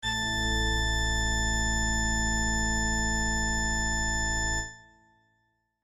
LA-110-a-5-octavas.mp3